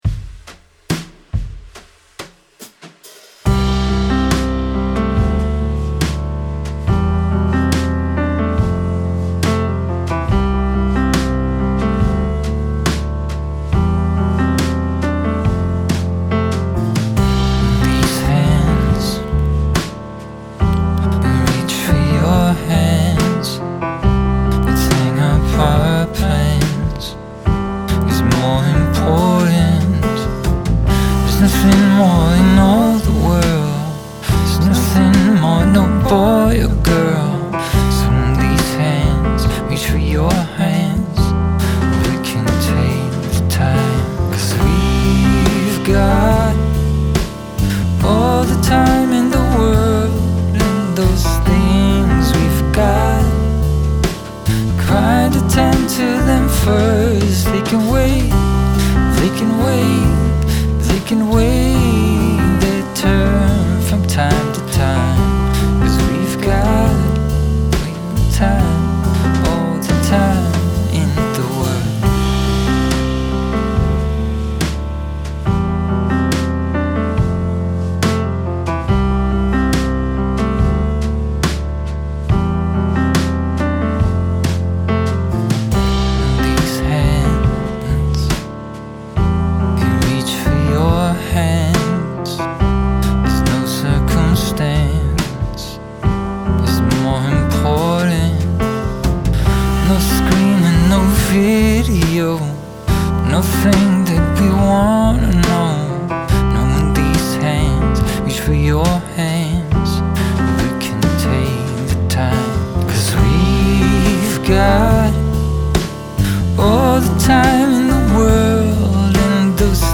A quickie this one with piano, a couple vox, brush drums and bass.
The drums and bass are all auto in Logic, with a little tweaking with sliders and options.
Fantastic use of repetition too, circling back to hands and time makes for a great singalong ballad.
I'm so glad I found this song! I love the message about prioritizing things that are important in life - and I love the way the melody floats above the steady keyboard/percussion, kind of like the steadiness of a solid relationship.
The piano riff (love it), the bass, subtle brush strokes... it all fits with the lyrics and melody.